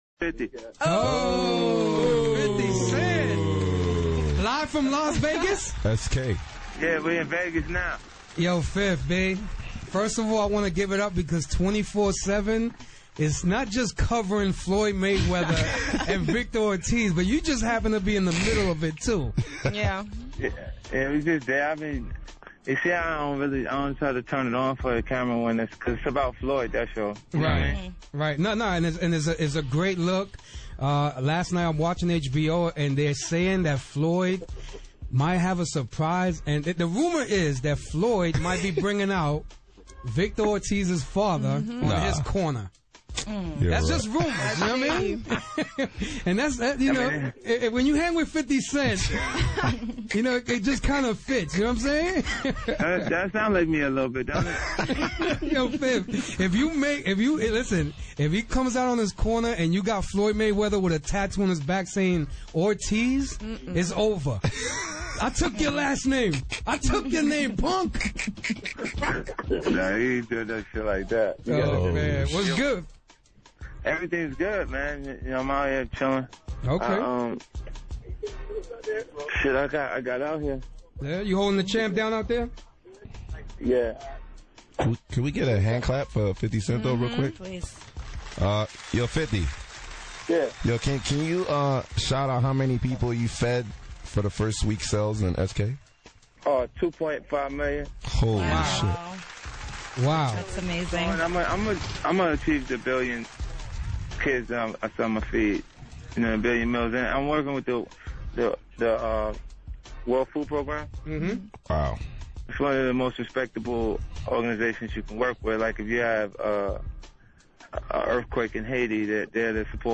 The day before his homie Floyd Mayweather’s bout with Victor Ortiz, Curtis phoned into Shade 45’s G-Unit radio to weigh in on the fight. He also discusses his Street King venture, feeding 2,5 million people, his SMS audio deal and next album.